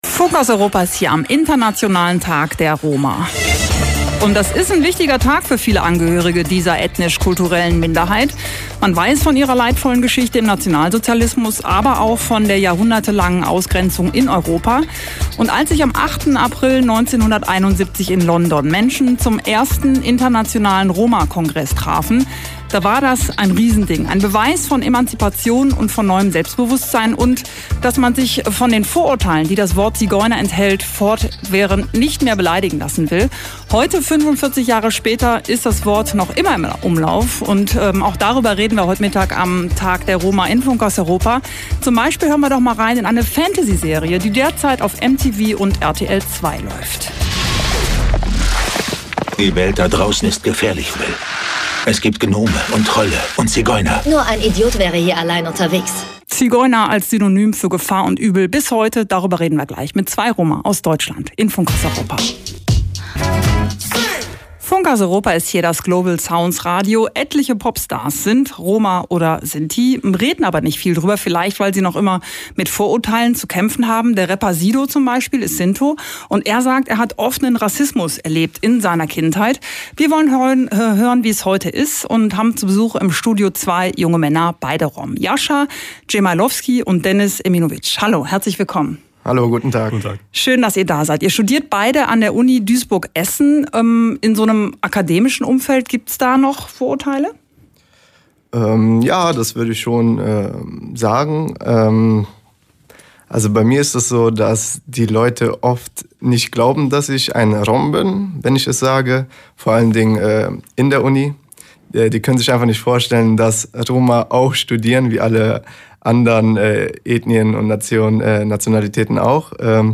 Interview Funkhaus Europa_Teil 1.mp3